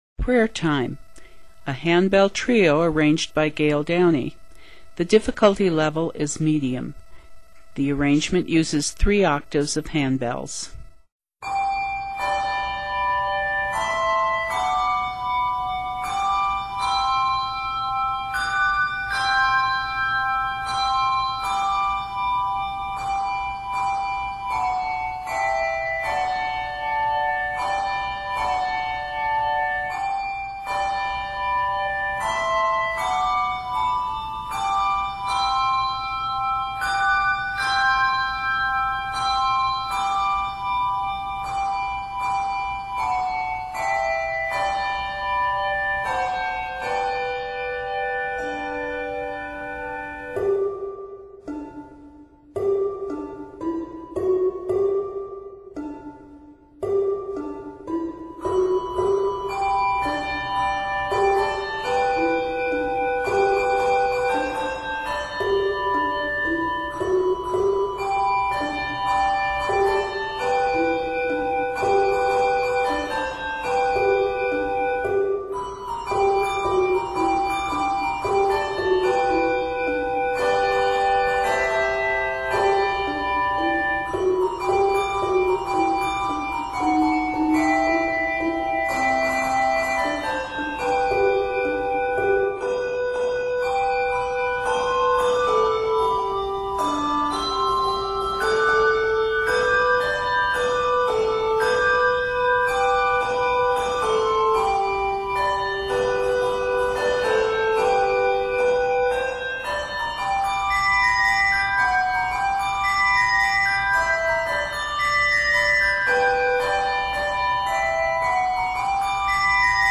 Mallets are called for in the second section.